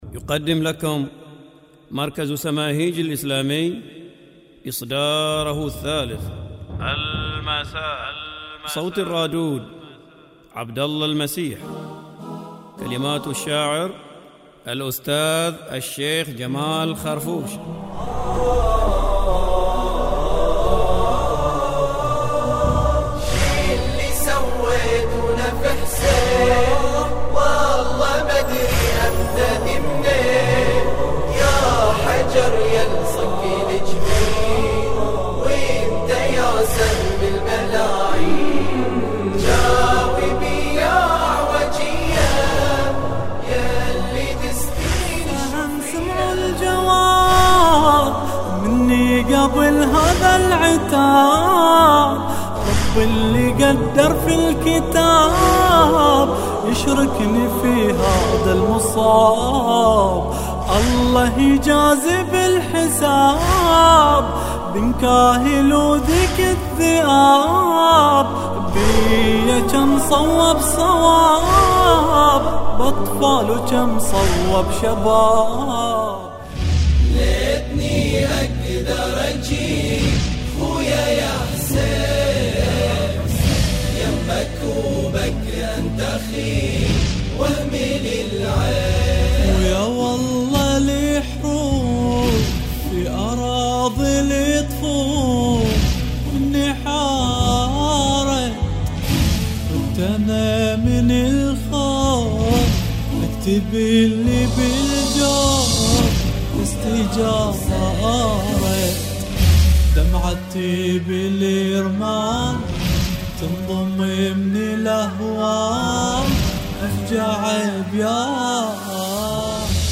الهندسة الصوتية و التوزيع الموسيقي
الكورال
مقاطع من إصدار